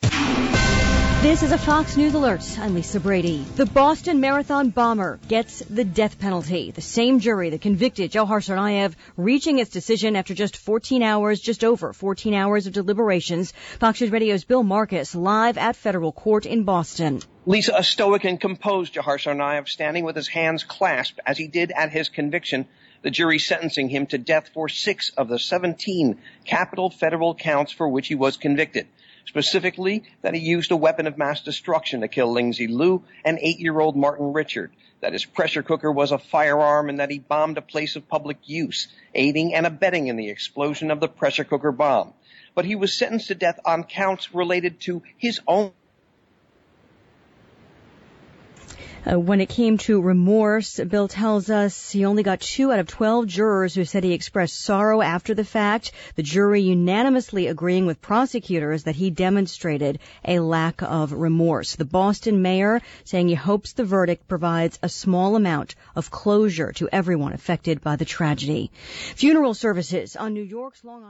LIVE 4PM –